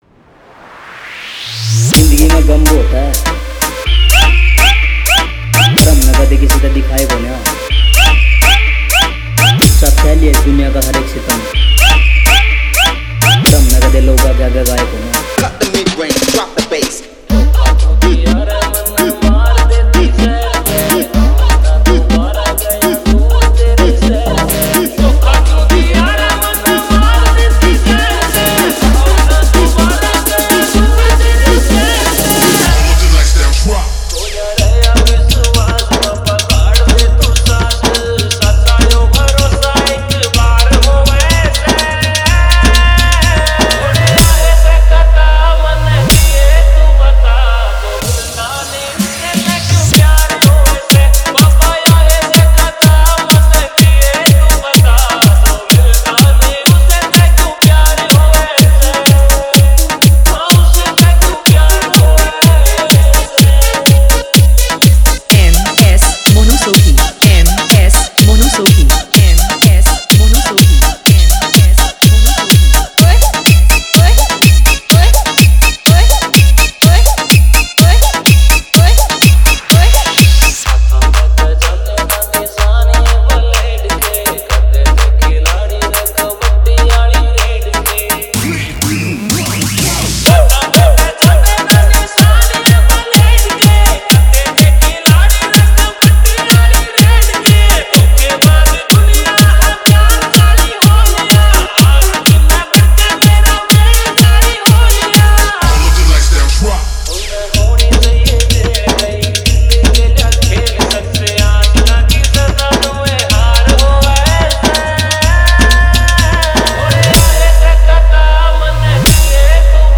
Haryanvi Remix Song